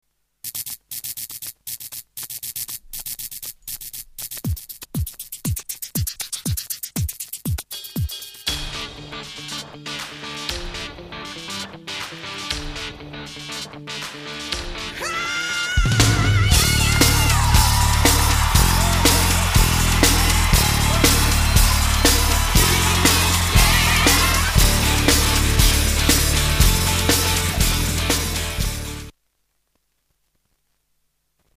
STYLE: Rock
pounds out the speakers with a fat slab of funky rock'n'roll